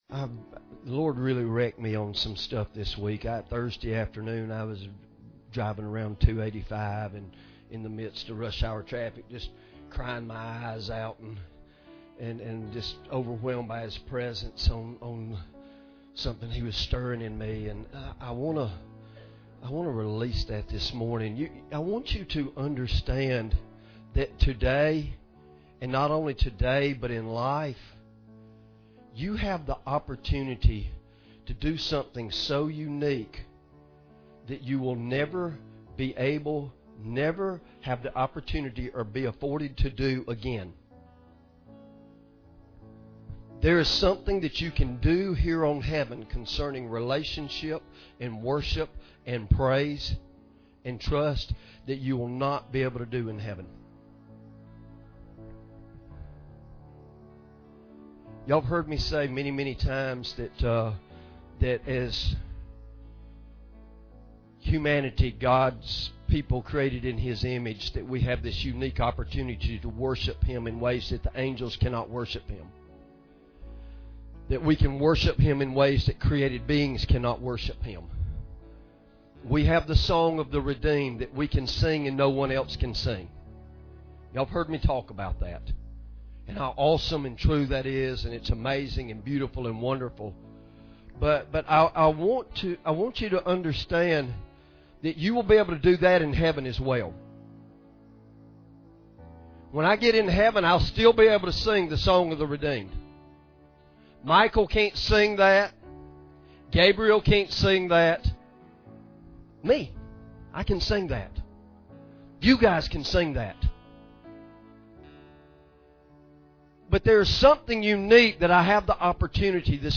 Sermon Audio Downloads | Victory Fellowship